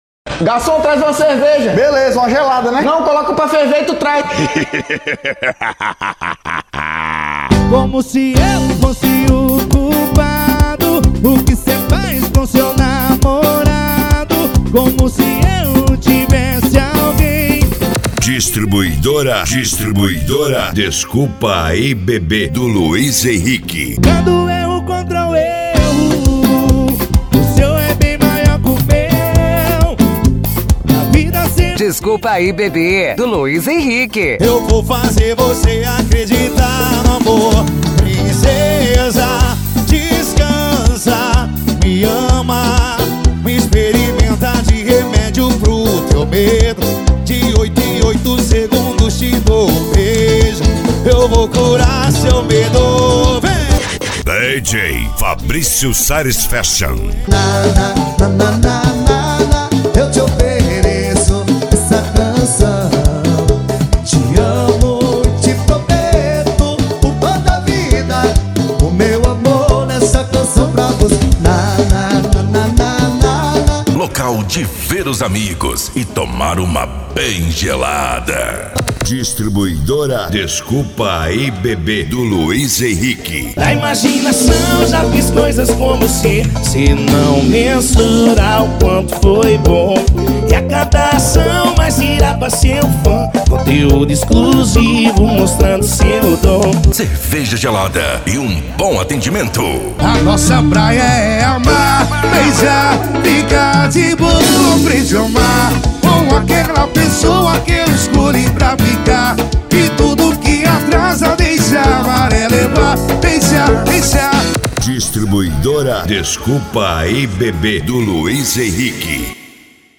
SERTANEJO